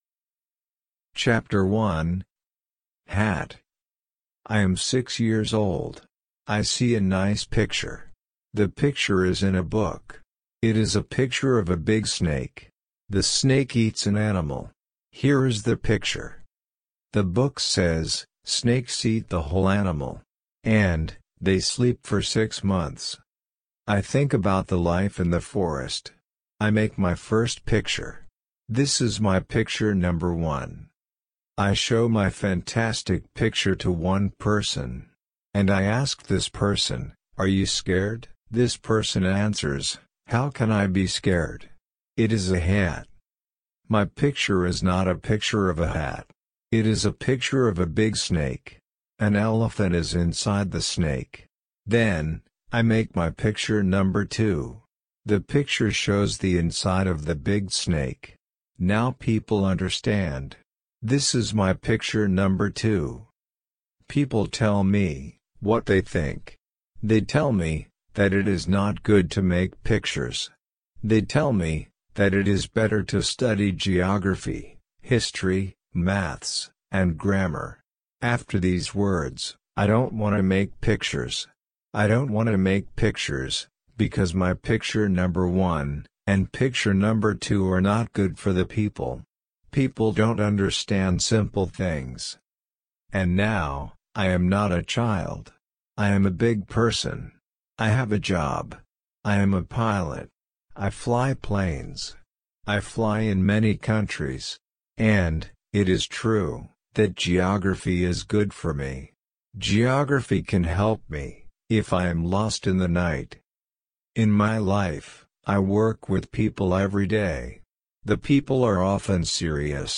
LP-L1-Ch1-slow.mp3